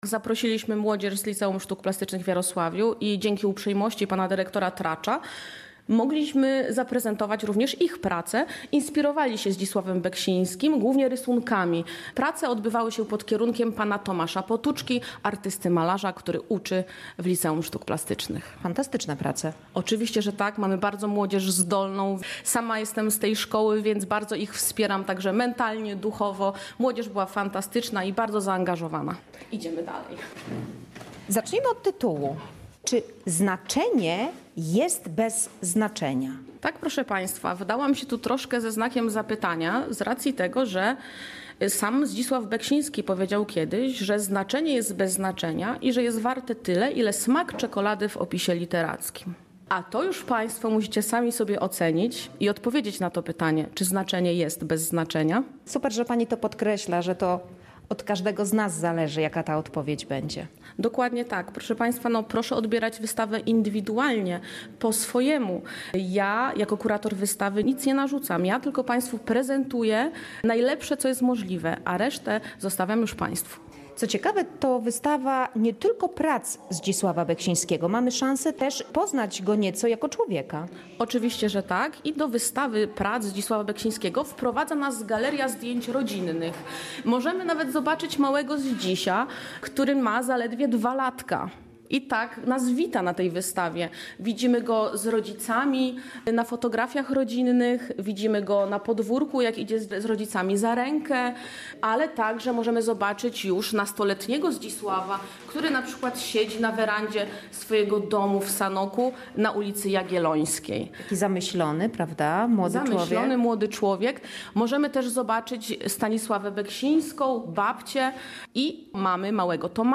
Podczas wernisażu